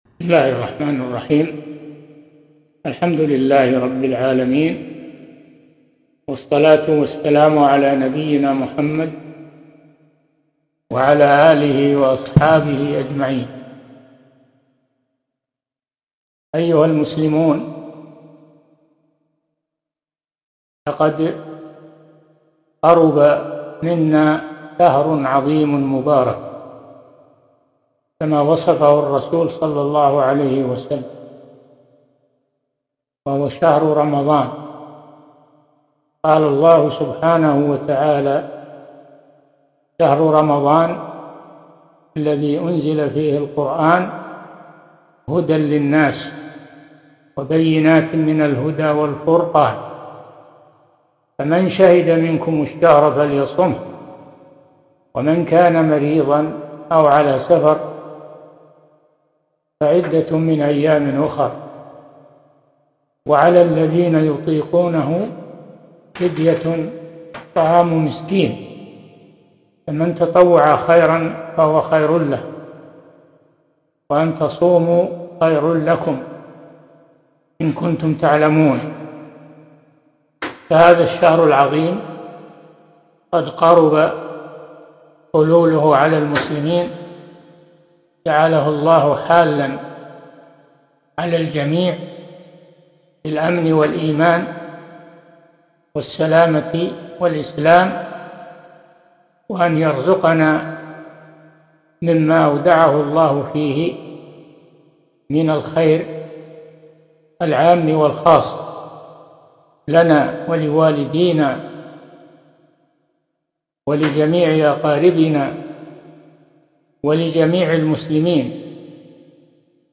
محاضرة - توجيهات رمضانية عبر البث المباشر 1441 هــ